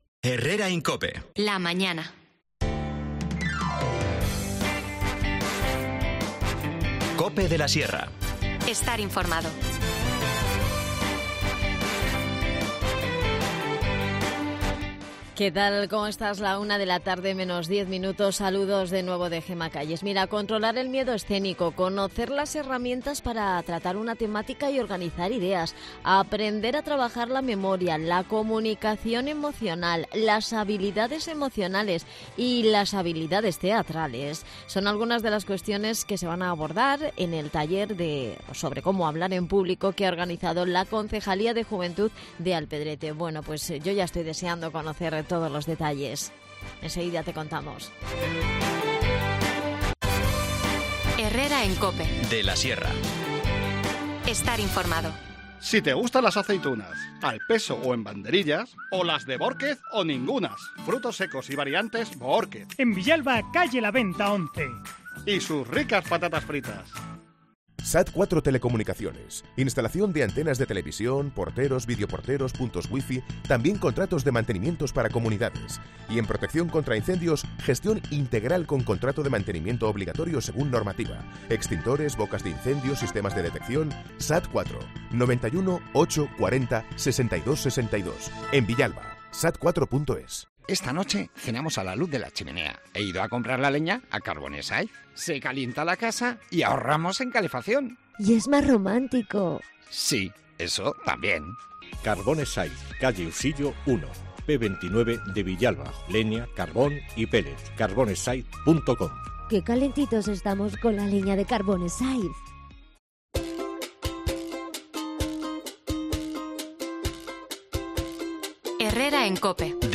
Nos cuenta todos los detalles David Losada, concejal de Juventud en Alpedrete.